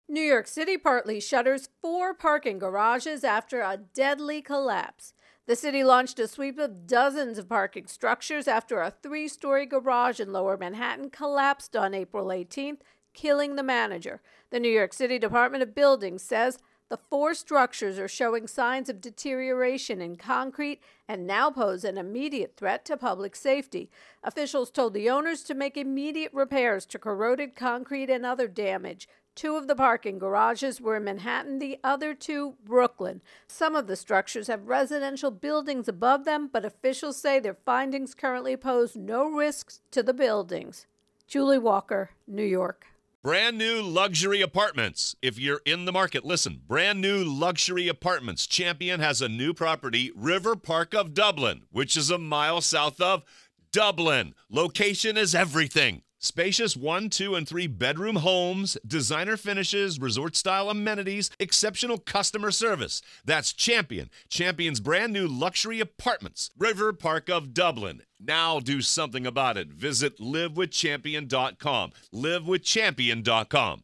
reports on Parking Garage Collapse